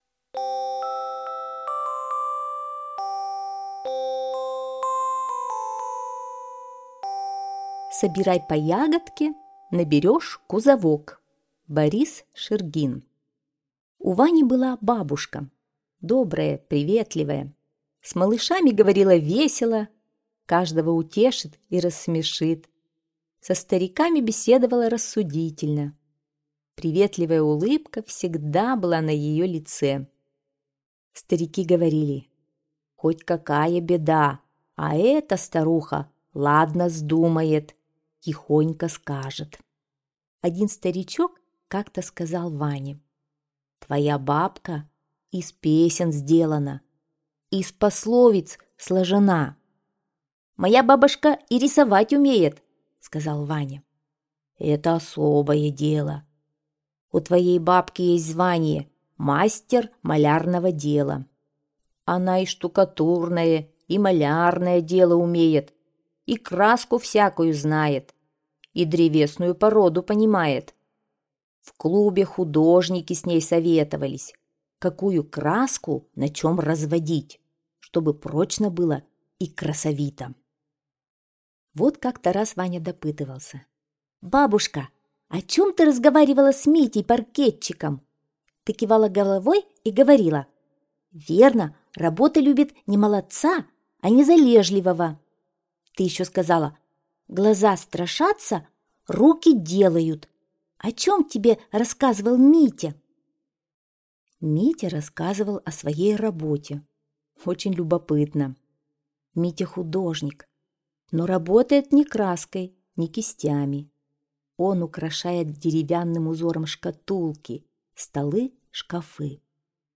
Аудиорассказ «Собирай по ягодке - наберешь кузовок»